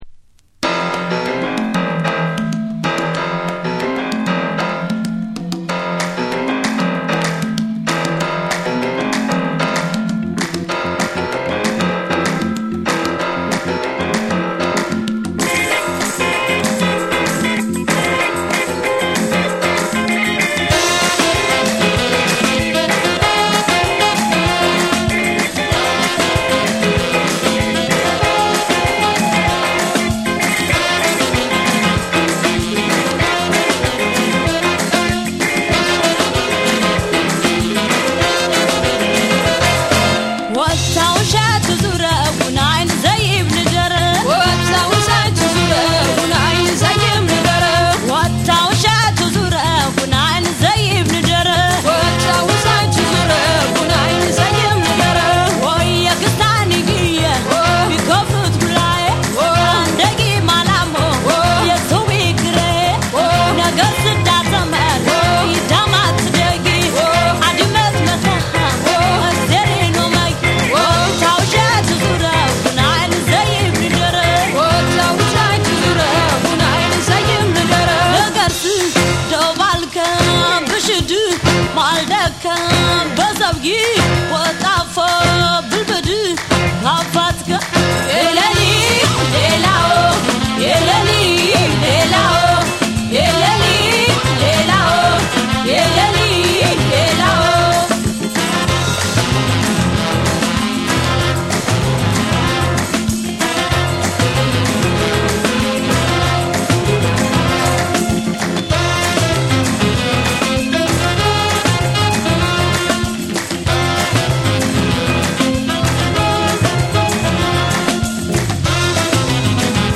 ジャズ、ファンク、ロック、民謡が独自に融合した“エチオ・グルーヴ”の真髄を、名曲・珍曲・発掘音源で網羅！